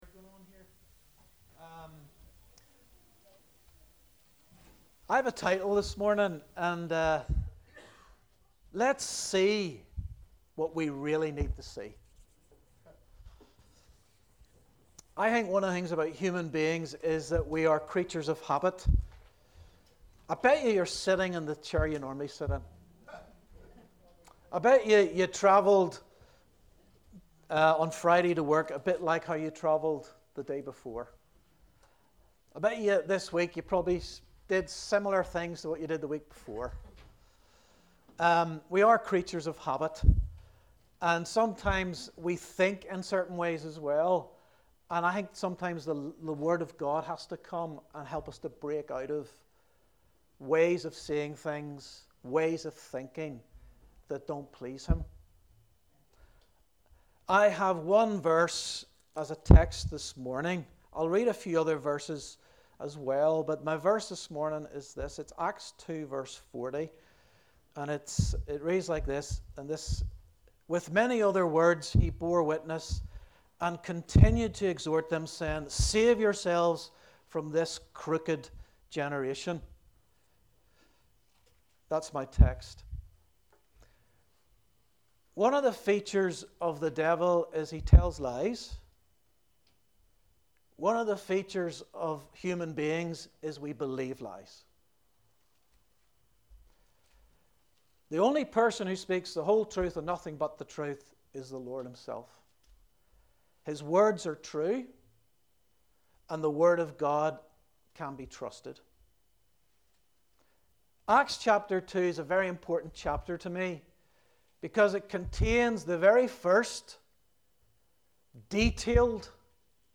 A message from the series "All Messages."